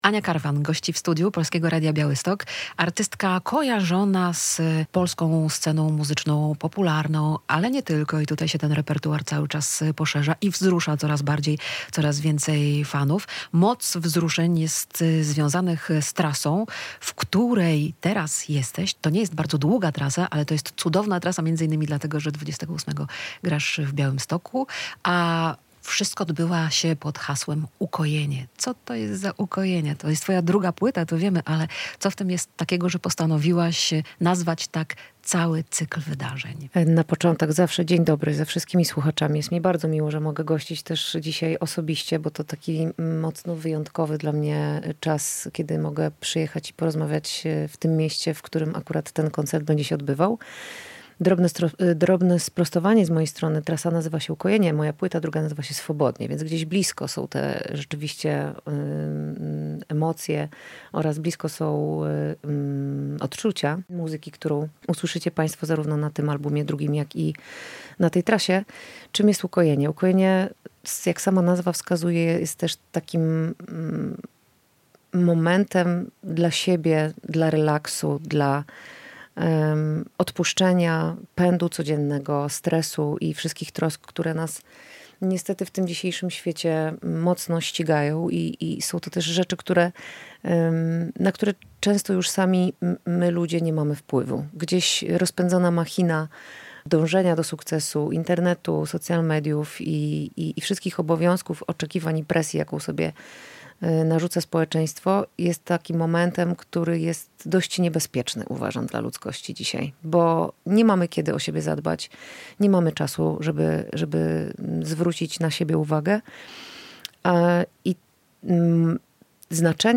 Ania Karwan - piosenkarka